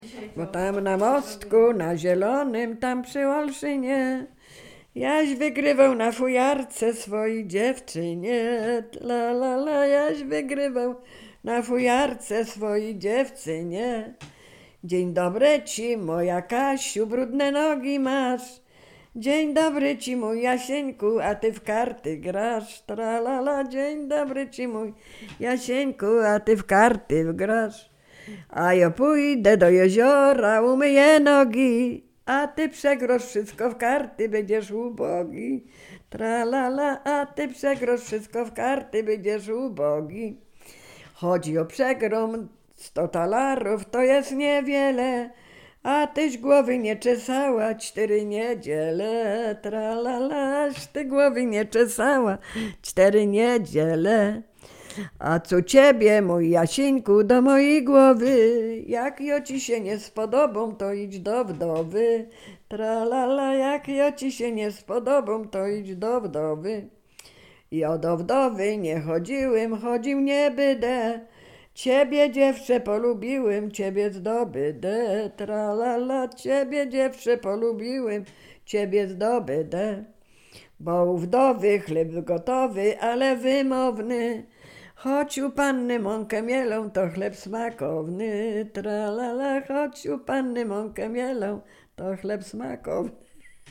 Sieradzkie
liryczne miłosne